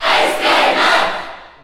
Category: Crowd cheers (SSBU) You cannot overwrite this file.
Ice_Climbers_Cheer_Japanese_SSBU.ogg.mp3